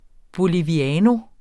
Udtale [ boliviˈæːno ]